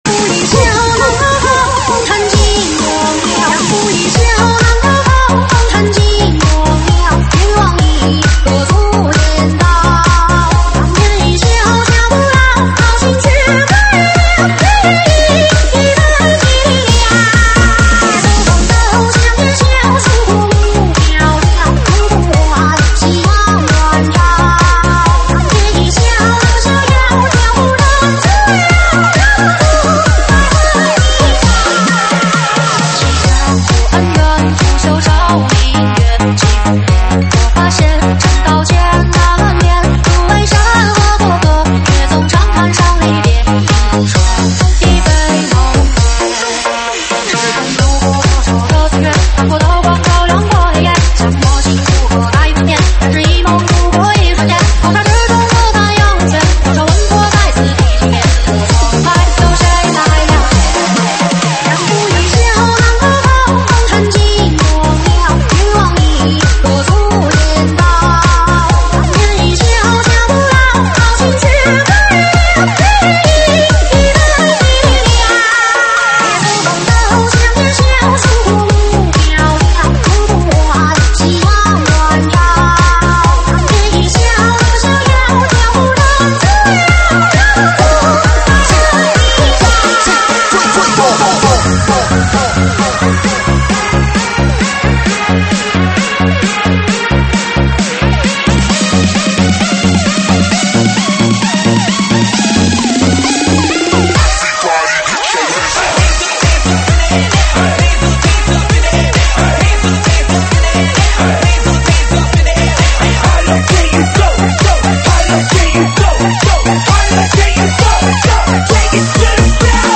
中文慢摇